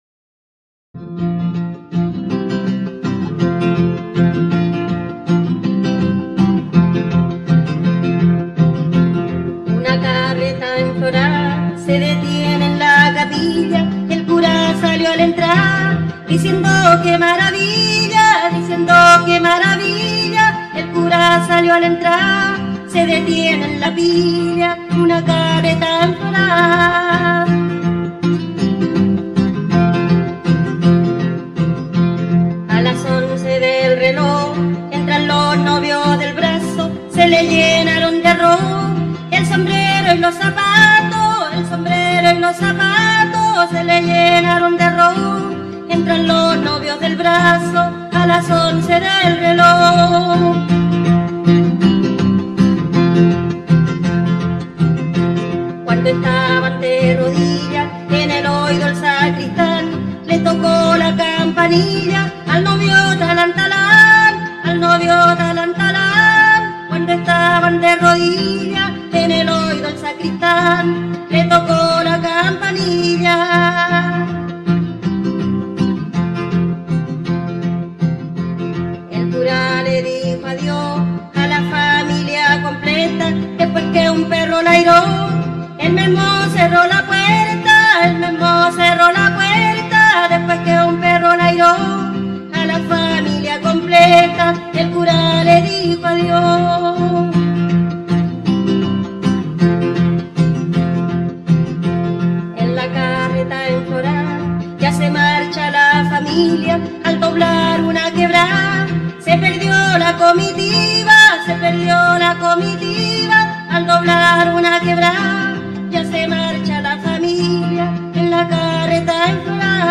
Paravienes al revés, tradicional chilena, recopilación de Violeta Parra (1964)